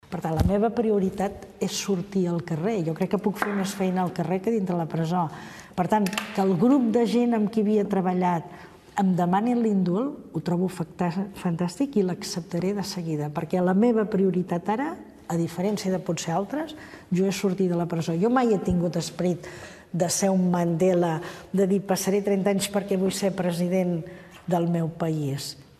L'exconsellera ho ha dit en una entrevista al programa Preguntes Freqüents de TV3, des de dins de la presó de Puig de les Basses